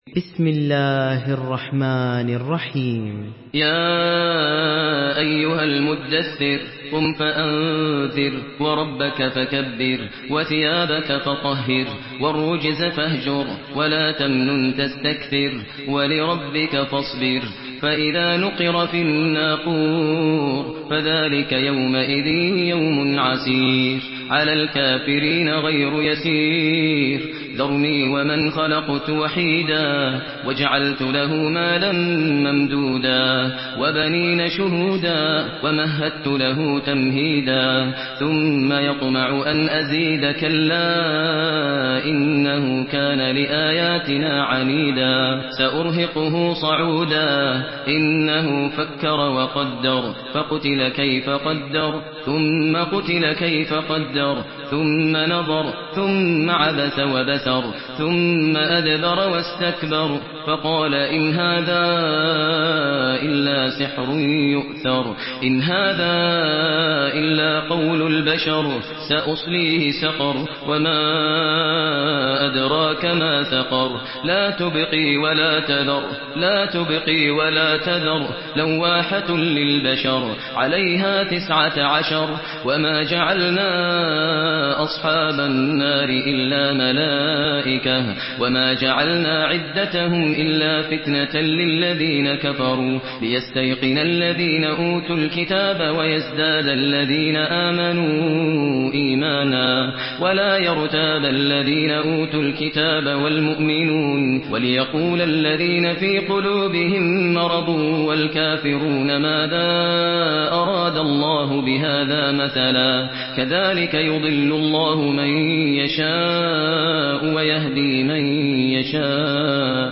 Surah المدثر MP3 in the Voice of ماهر المعيقلي in حفص Narration
مرتل